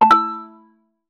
notification_sounds